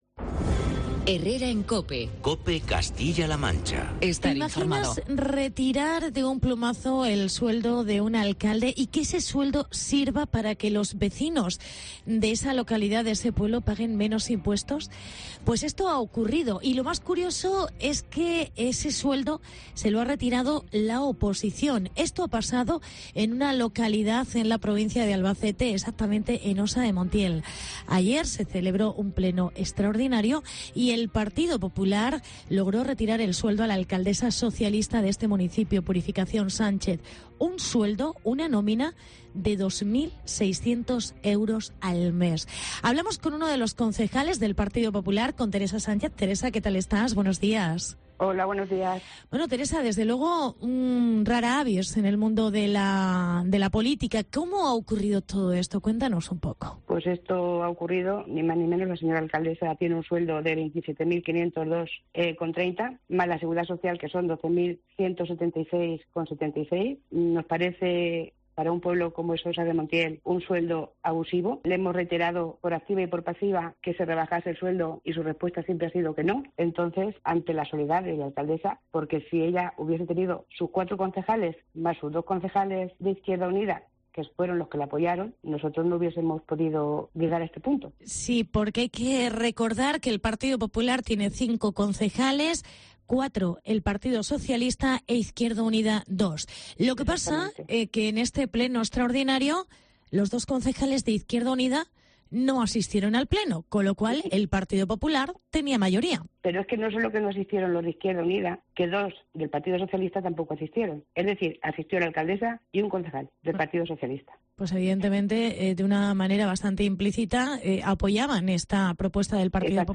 Hablamos con la concejal del PP. Teresa Sánchez